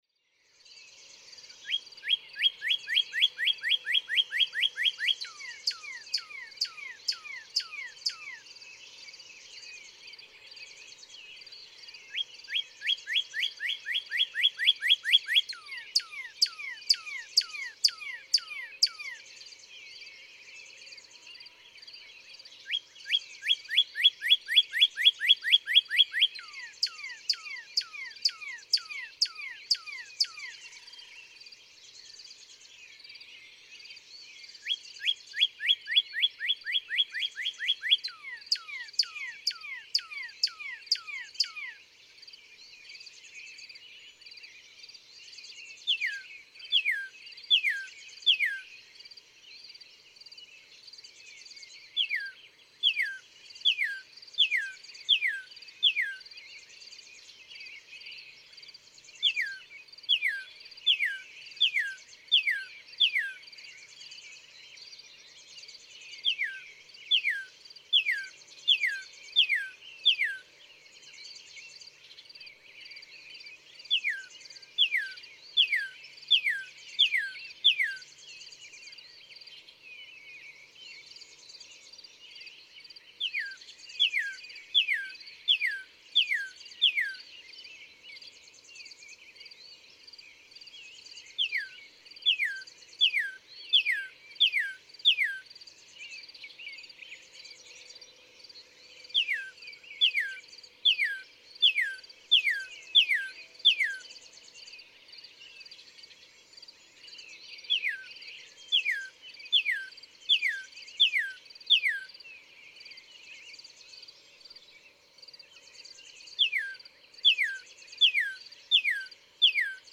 Northern cardinal
Here are eight different songs from this male, with each one repeated multiple times before the next is introduced. Listen carefully and you will hear him switch to a new song at the following times: 0:45, 2:14, 3:30, 5:37, 6:06, 6:44, and 7:36.
Hatfield, Massachusetts.